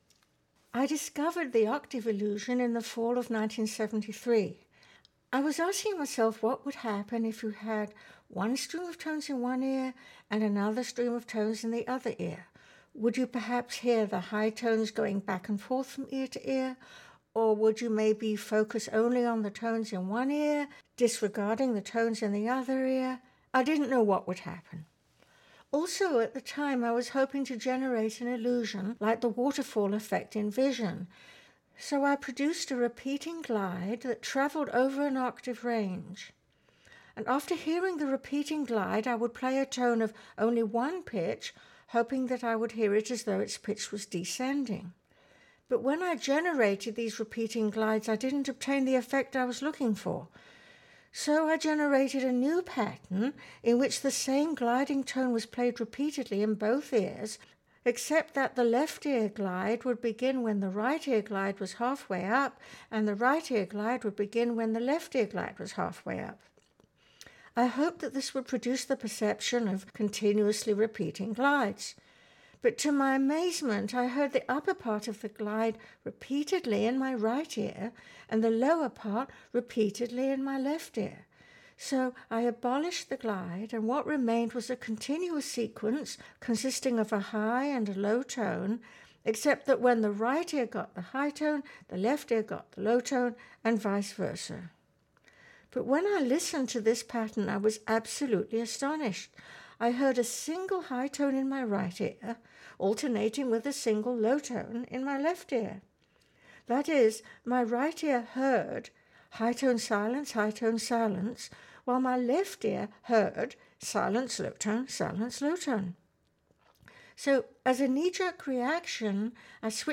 In this final narrative, Dr. Deutsch recalls the discovery process for the octave illusion.